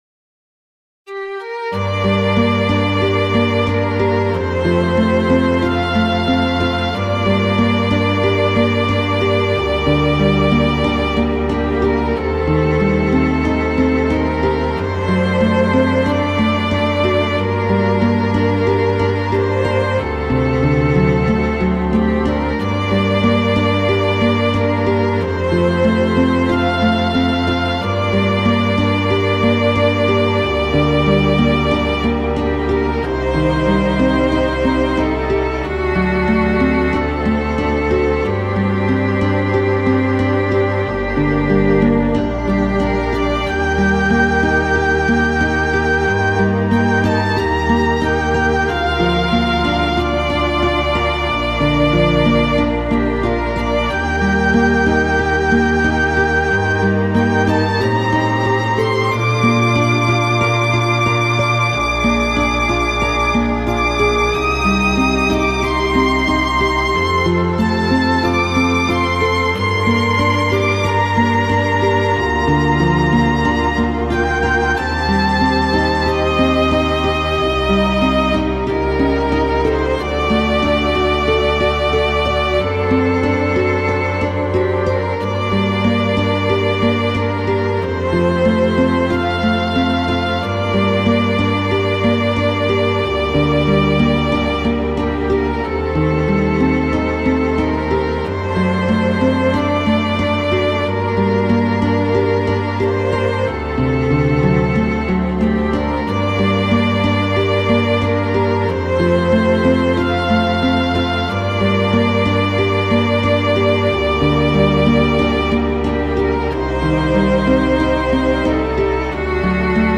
クラシックファンタジーロング暗い